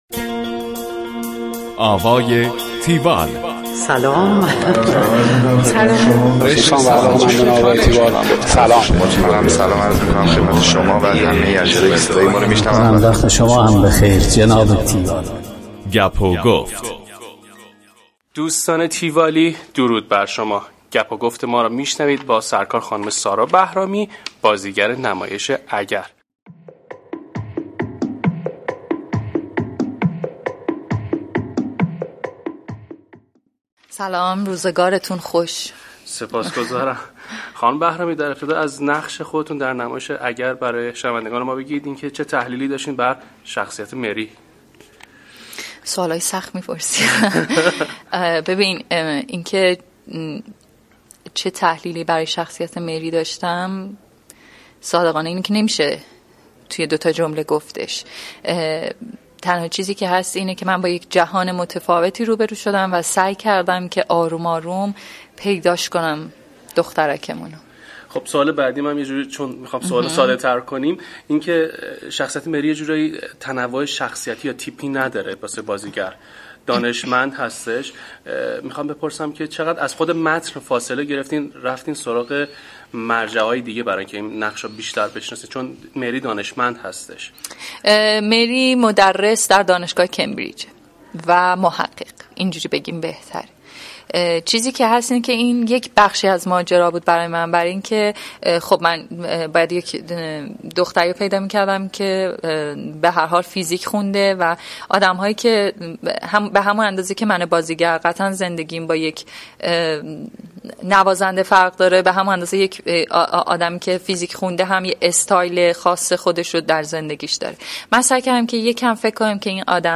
گفتگوی تیوال با سارا بهرامی